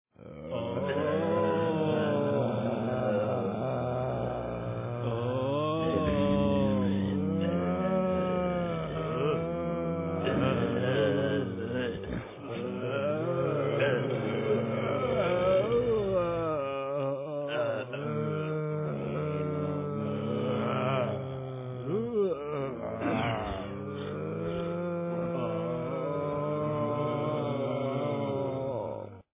zombie.mp3